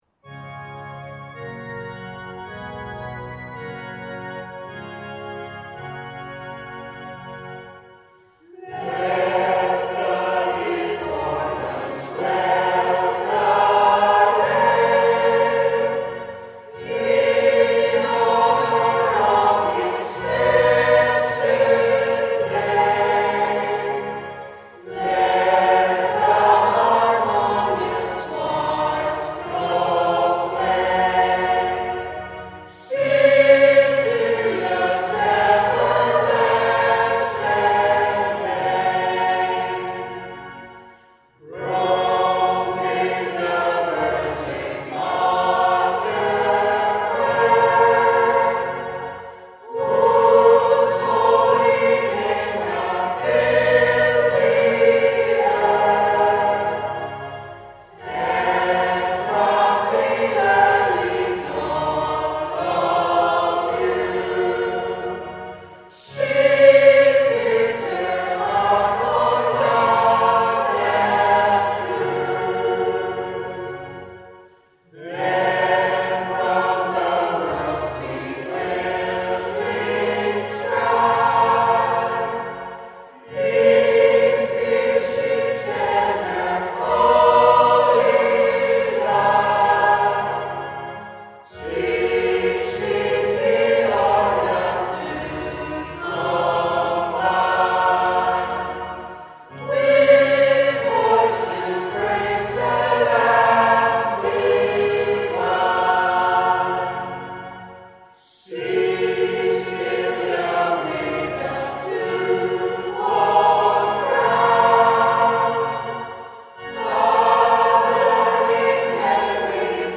A song in honour of Saint Cecilia: Let's Pray with Catholic Filipino Australia - Brisbane Choir 2:32
On 22 November, the Church celebrates the Feast of St Cecilia, the beloved patron saint of music and musicians. To mark this joyful occasion, the Brisbane Catholic Filipino Choir offer a beautiful hymn in her honour, "Let the Deep Organ Swell the Lay," composed by Roman Catholic priest Charles Constantine Pise.